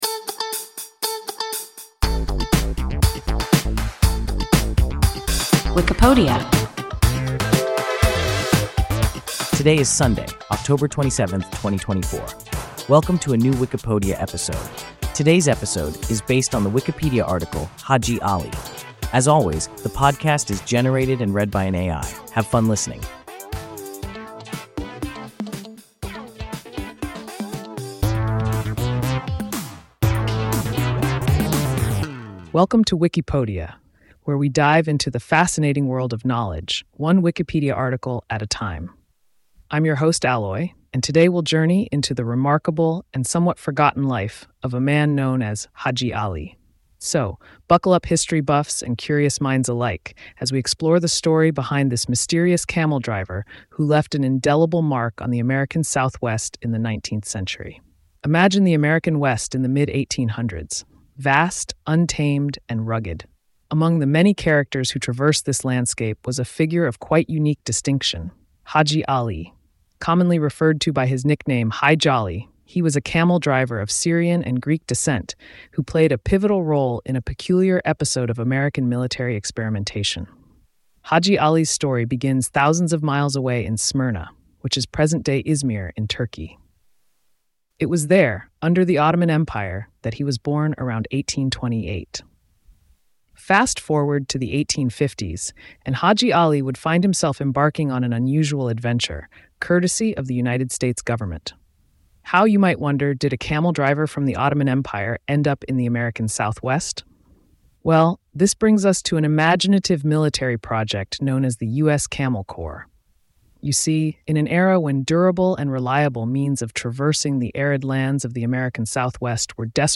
Hadji Ali – WIKIPODIA – ein KI Podcast